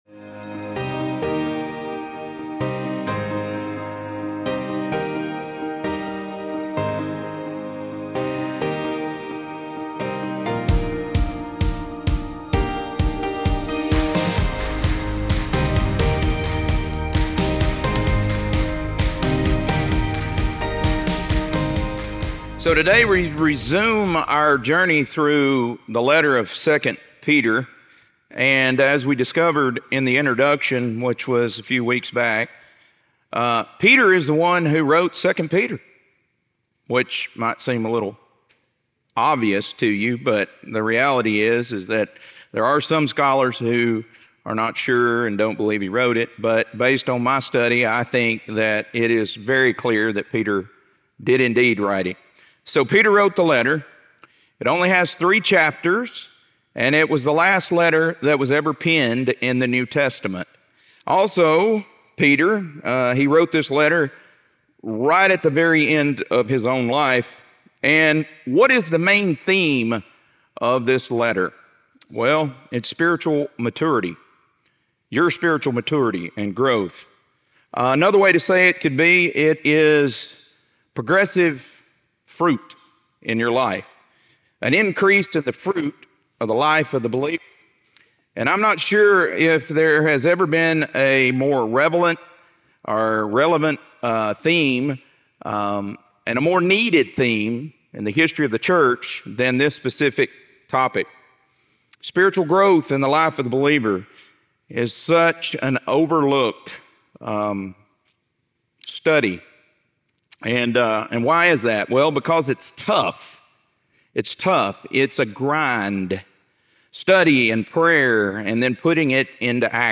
2 Peter - Lesson 1D | Verse By Verse Ministry International